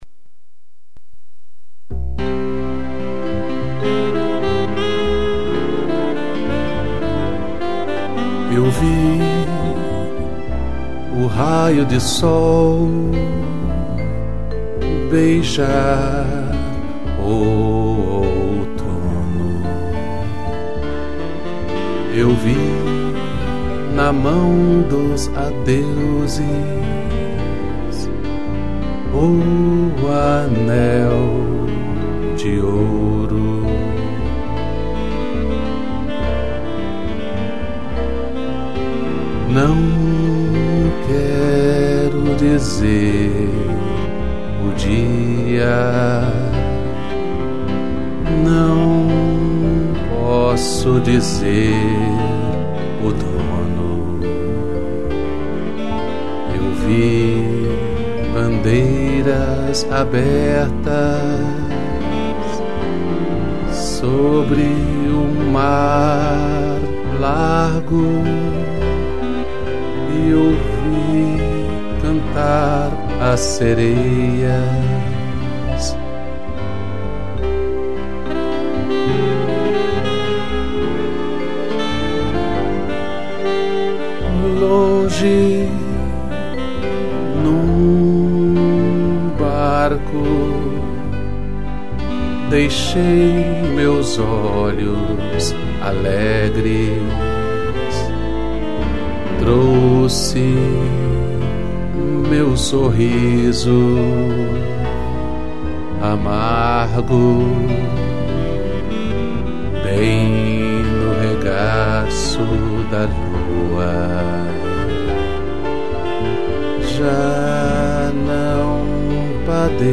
vibrafone, piano, sax e strings